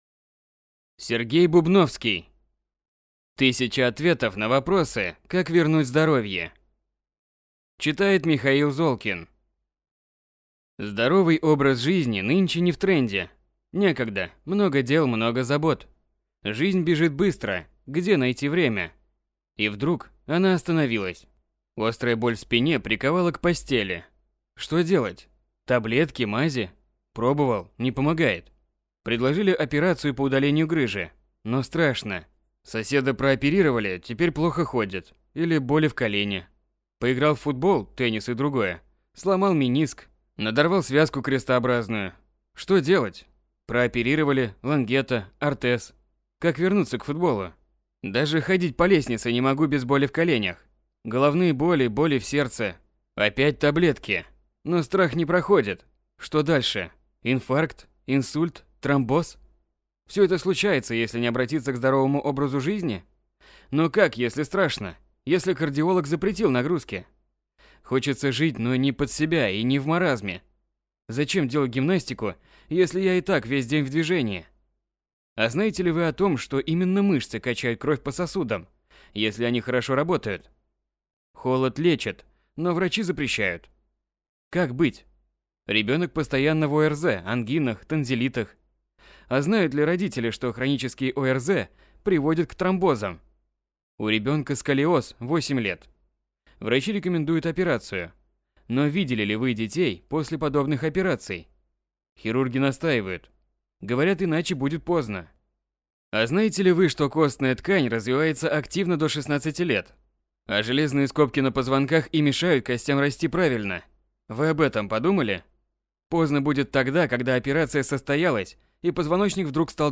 Аудиокнига 1000 ответов на вопросы, как вернуть здоровье | Библиотека аудиокниг